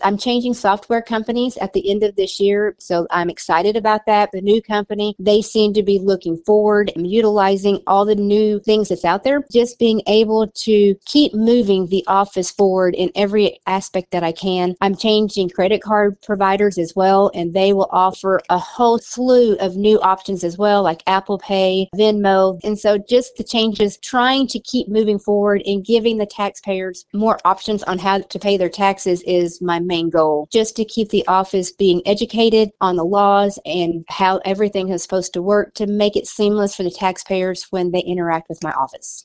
When asked what the goals for her next term look like, Smith details what is most important to her.